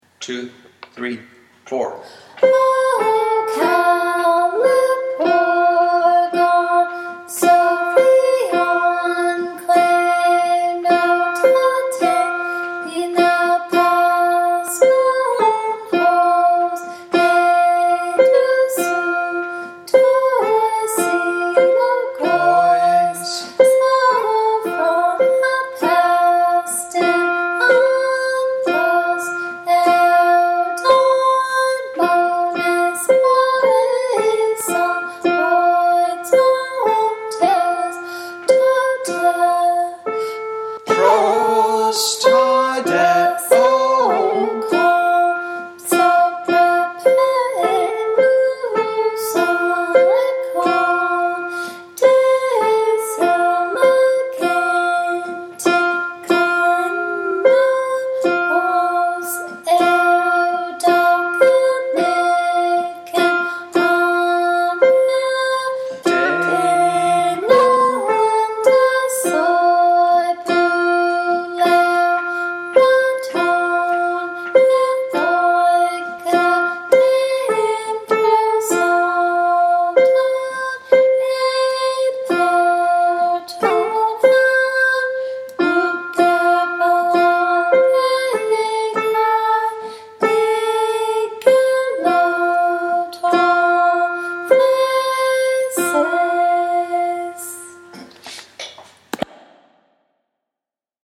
The music for Chorus 2
CloudsChorus2.mp3